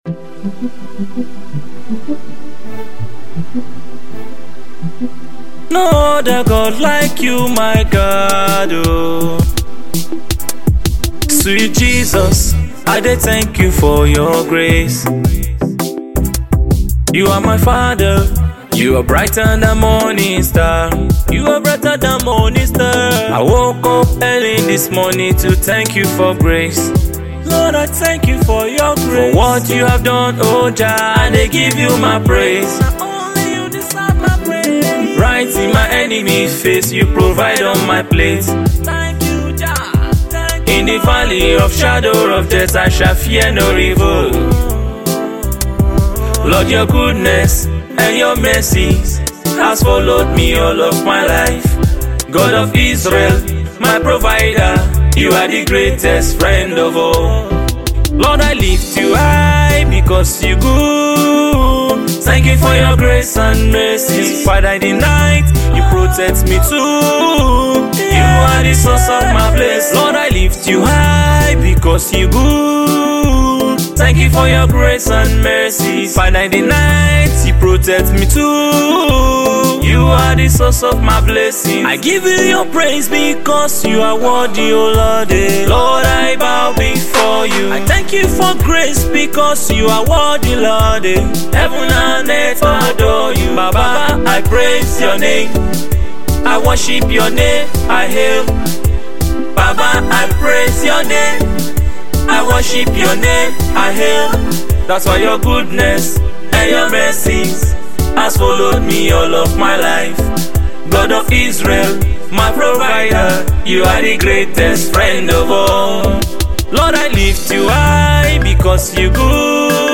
gospel
He infuses elements of reggae into this song.